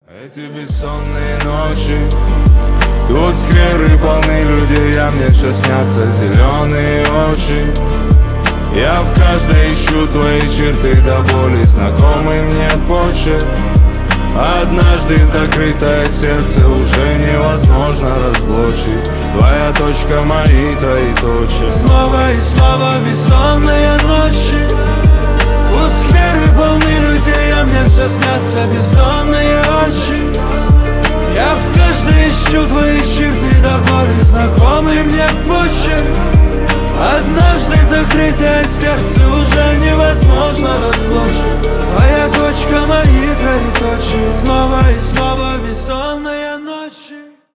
поп
романтические
грустные